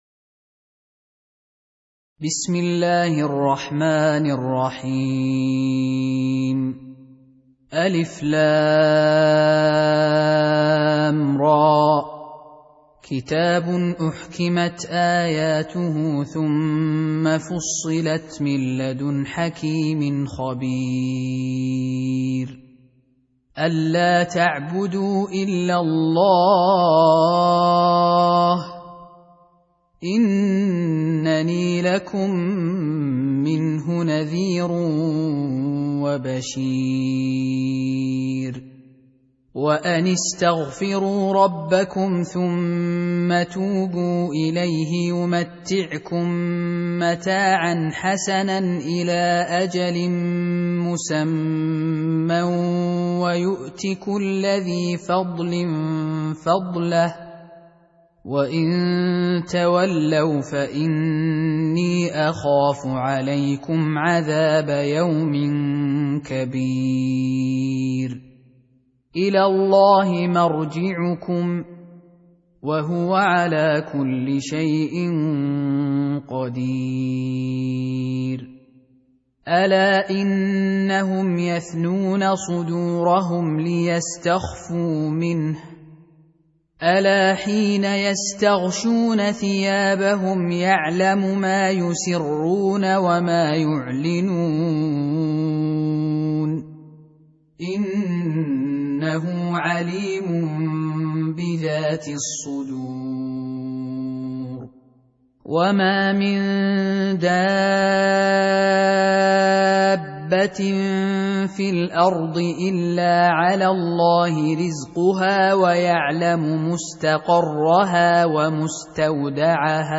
Surah Repeating تكرار السورة Download Surah حمّل السورة Reciting Murattalah Audio for 11. Surah H�d سورة هود N.B *Surah Includes Al-Basmalah Reciters Sequents تتابع التلاوات Reciters Repeats تكرار التلاوات